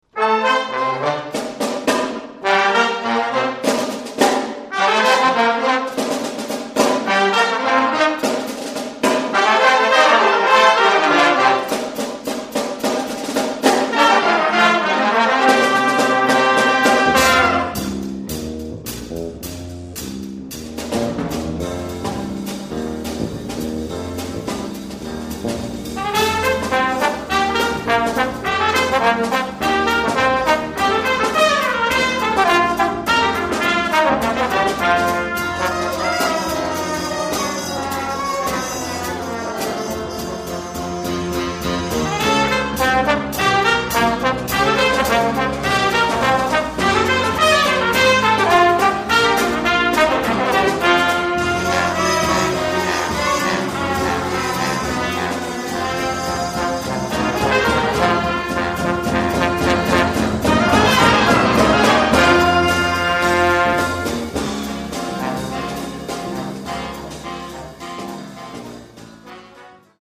The accelerando needs to be worked on.